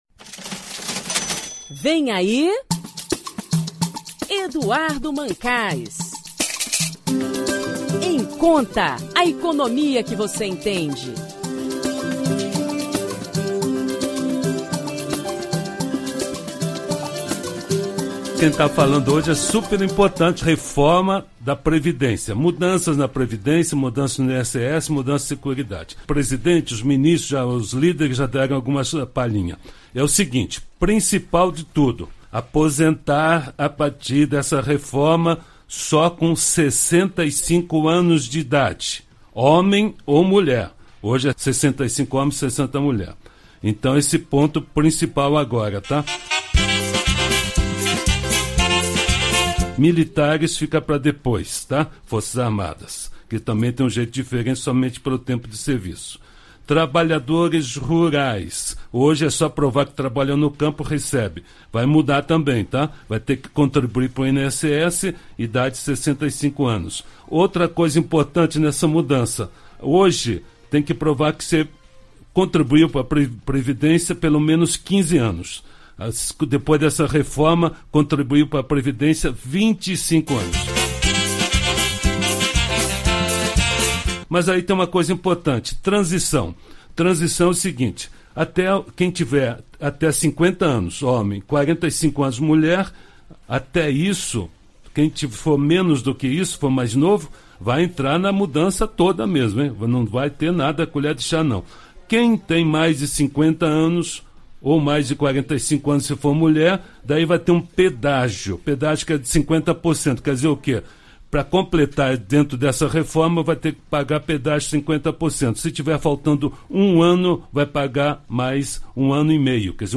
O Em Conta apresenta trechos do pronunciamento do Presidente Michel Temer e da entrevista coletiva, realizada hoje, do secretário de Previdência Social do Ministério da Fazenda , Marcelo Caetano .